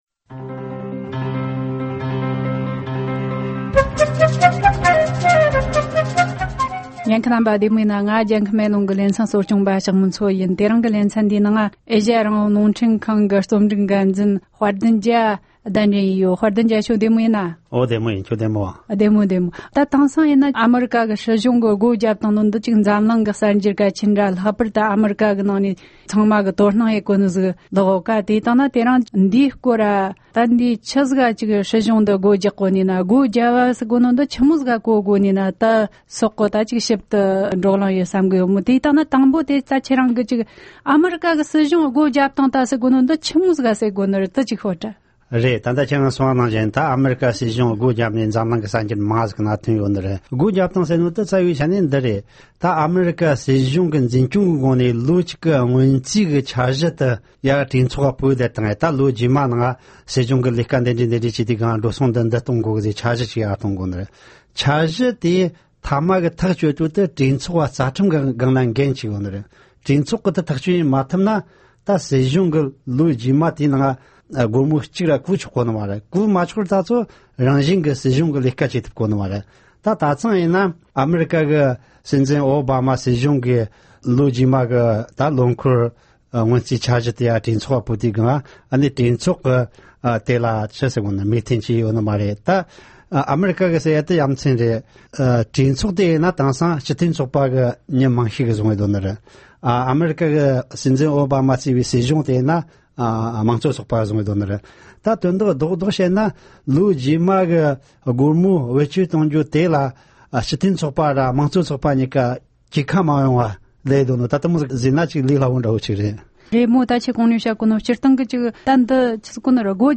གླེང་མོལ་བྱས་བར་ཉན་རོགས་གནོངས།།